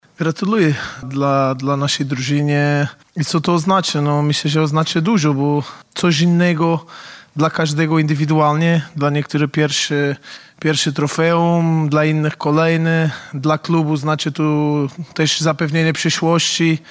O tym co znaczy to trofeum mówił trener Legii Warszawa- Goncalo Feio